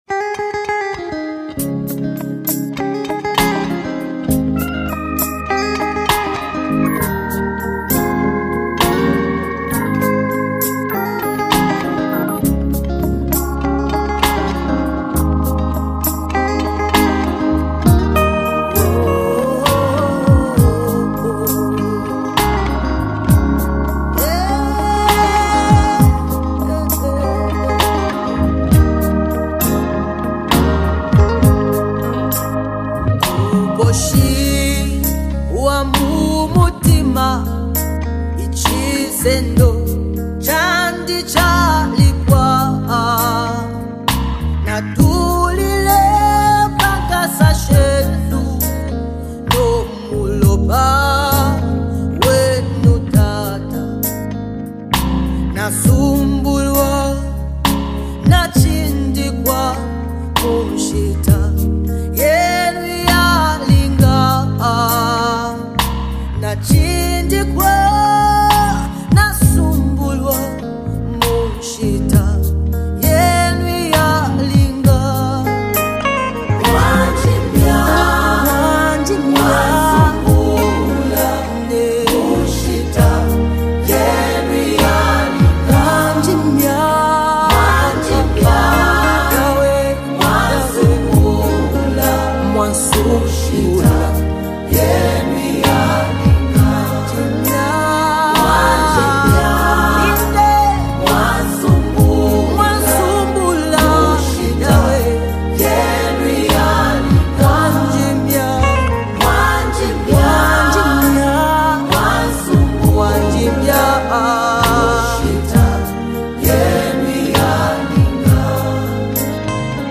Zambia's celebrated gospel singer
triumphant track
create an atmosphere of triumph and celebration.